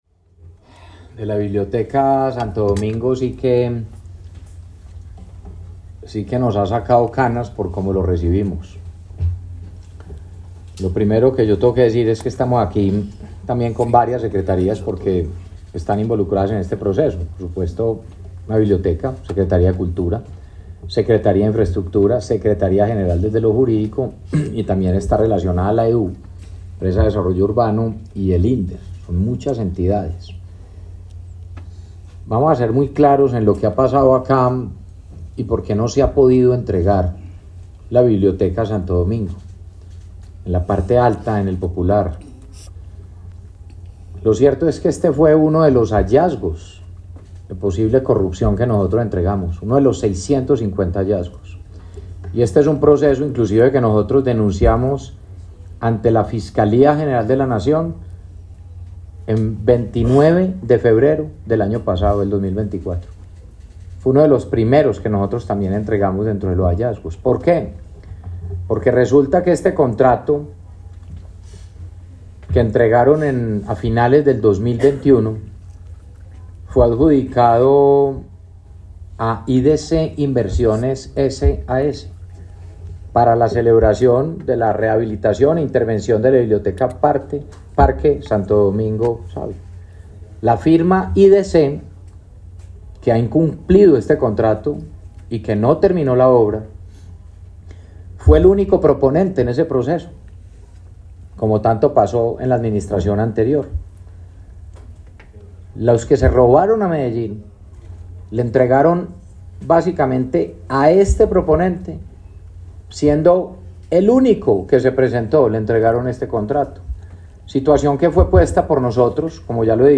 Declaraciones-alcalde-de-Medellin-Federico-Gutierrez-3.mp3